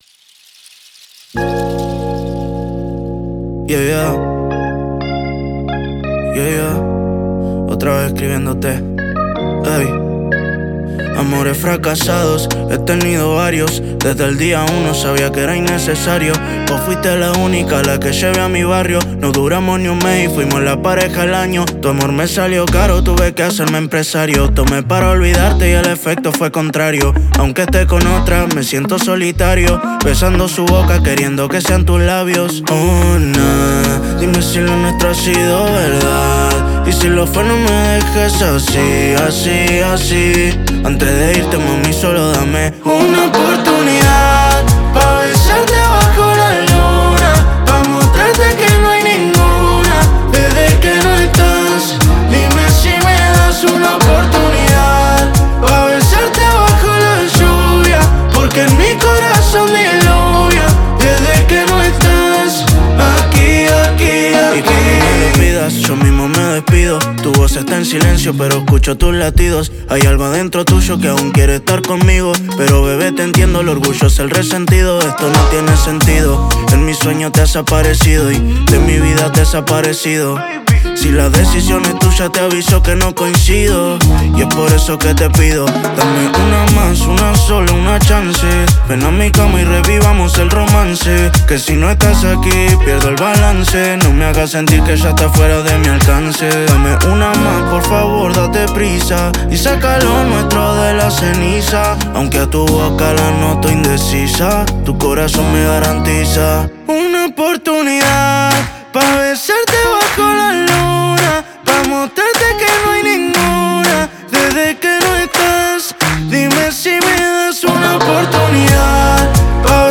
afro